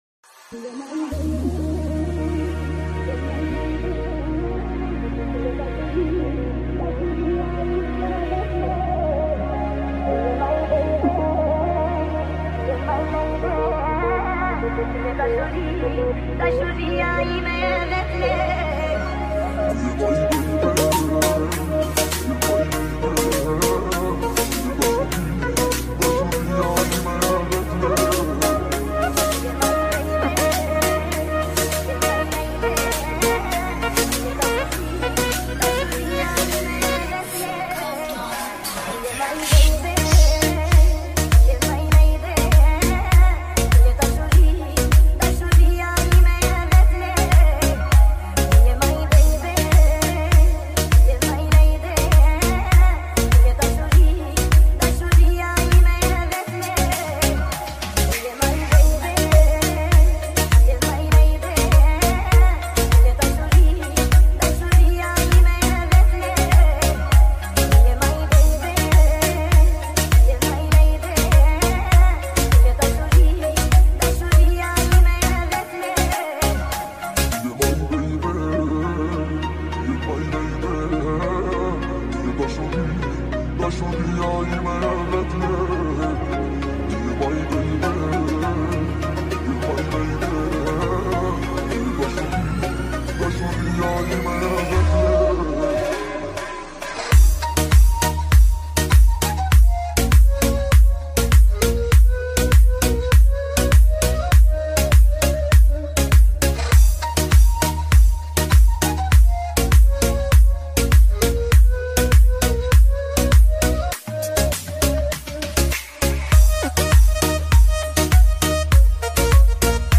میکس عربی شاد تند بیس دار برای ماشین